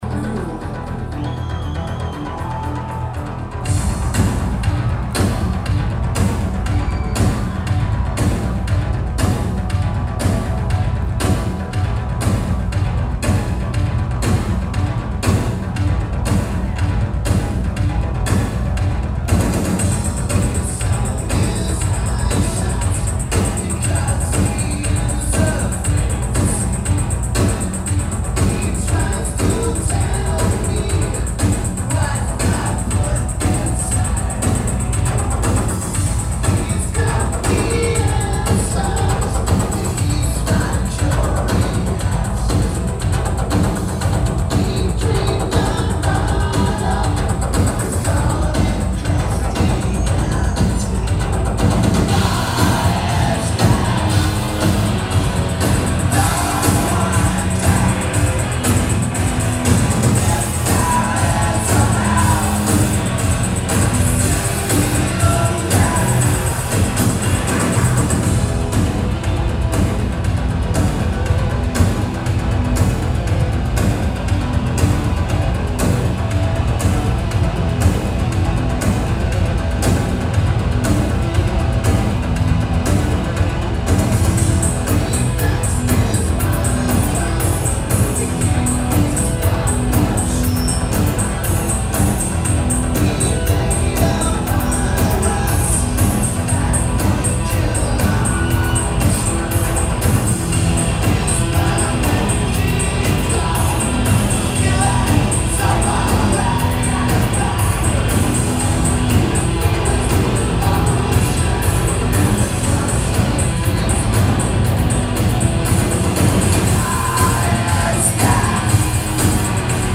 Huntridge Theatre
This is a 2nd gen DAT tape. Good recording.